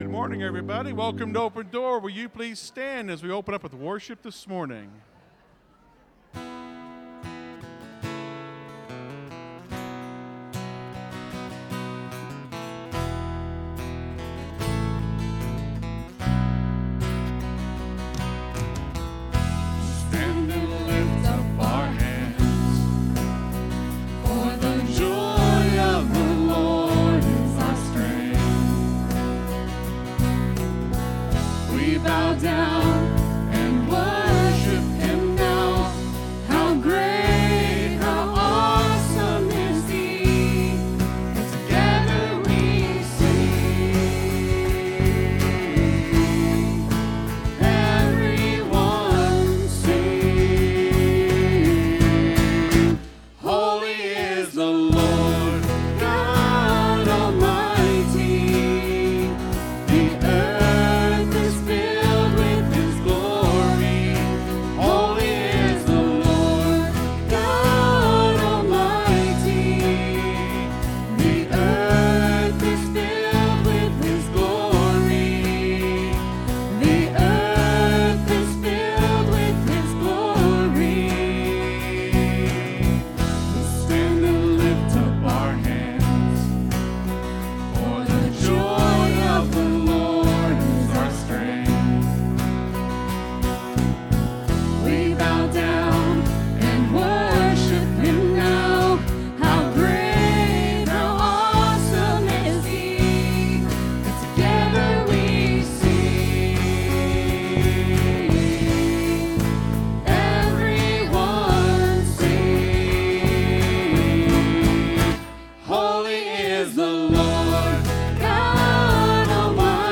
(Sermon starts at 21:50 in the recording).